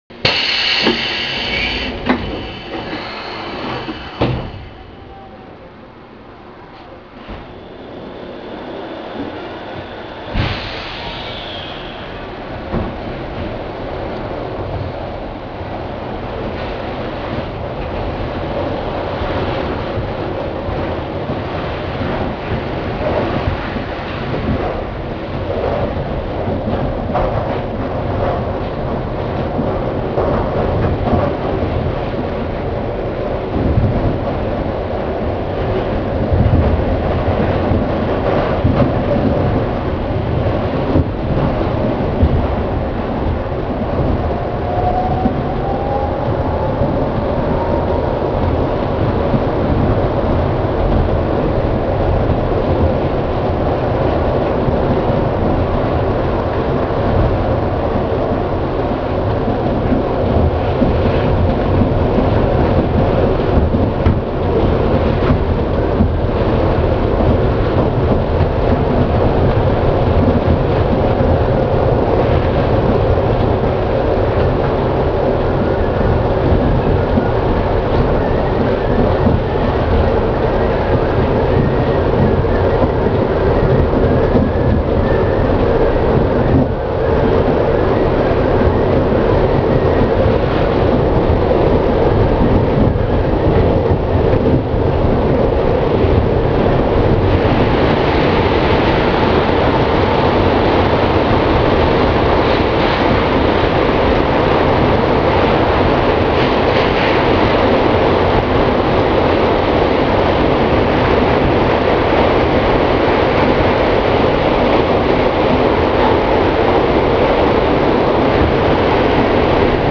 ・500形走行音
【江ノ島線】湘南藤沢→西鎌倉（3分20秒：1.06MB）
界磁チョッパを採用。随分と賑やかな走行音です。
かなり揺れるので収録も要注意。